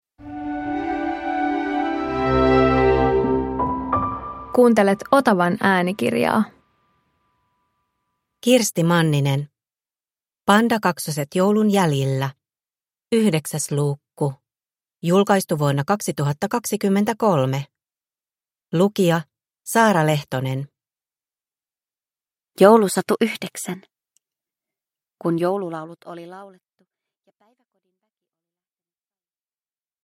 Pandakaksoset joulun jäljillä 9 – Ljudbok